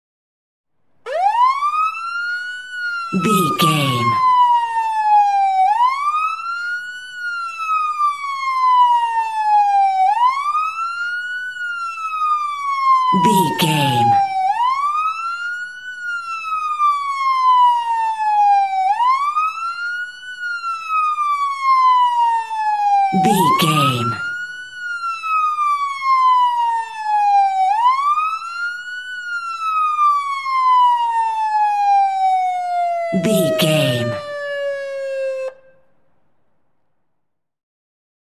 Ambulance Ext Large Siren
Sound Effects
urban
chaotic
anxious
emergency